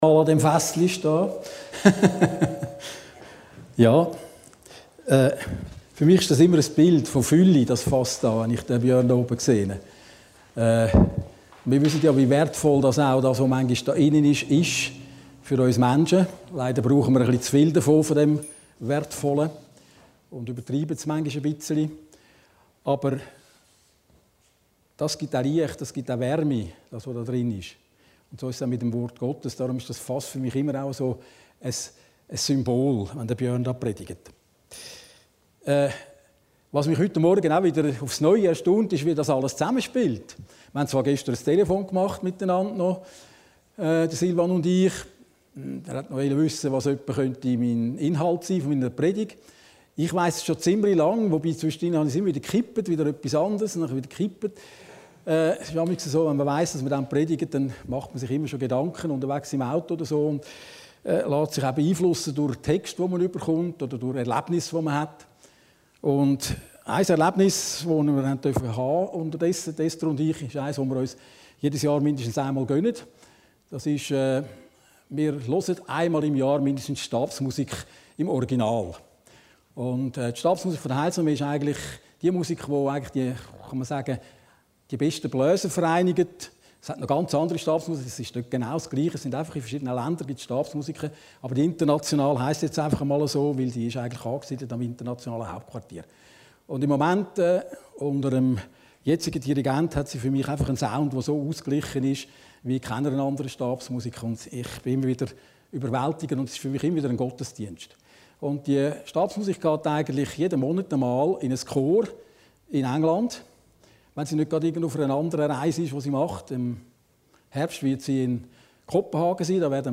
Predigten Heilsarmee Aargau Süd – Der Heilige Krieg